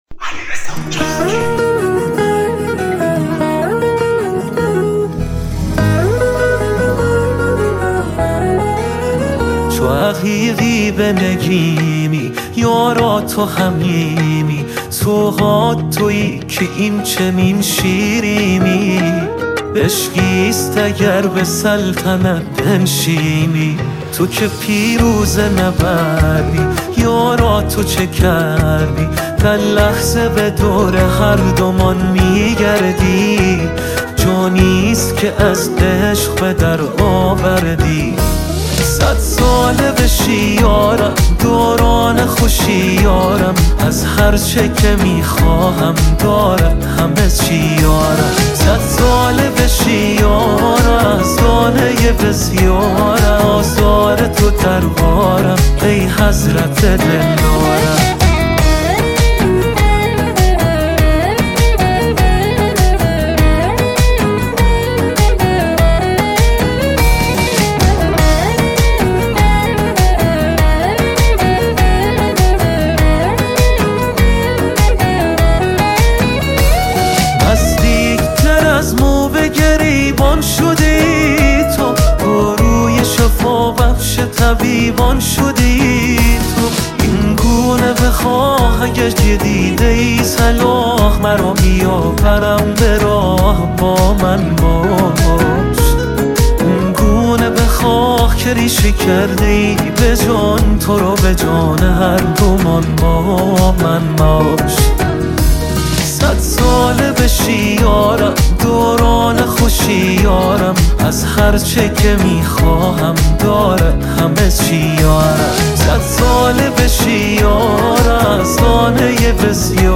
آهنگ پاپ ایرانی